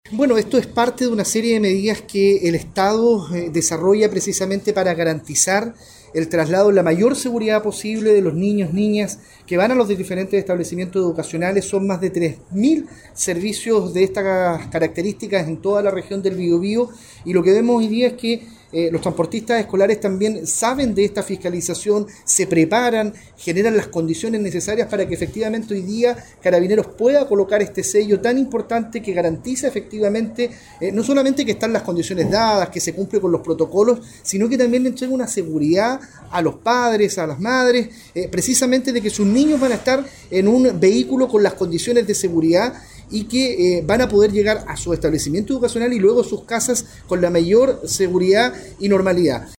cuna-1-Delegado-eduardo-pacheco-fiscalizacion-transporte-escolar.mp3